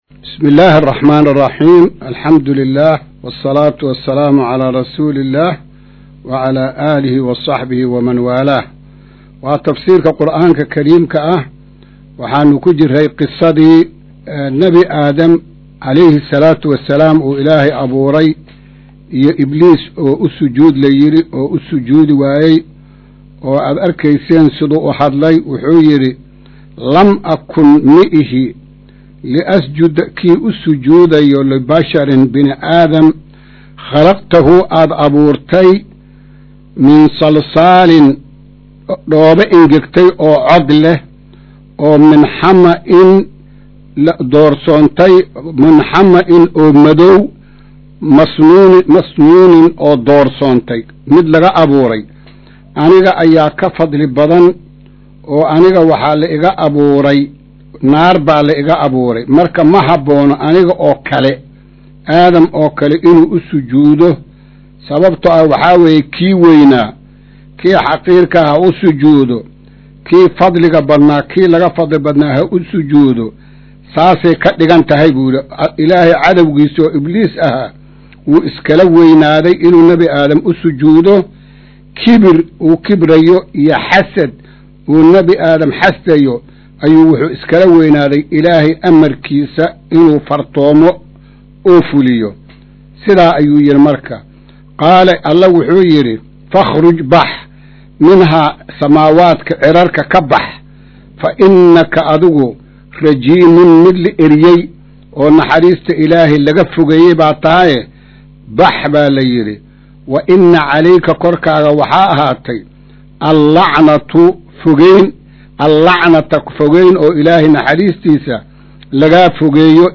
Maqal:- Casharka Tafsiirka Qur’aanka Idaacadda Himilo “Darsiga 129aad”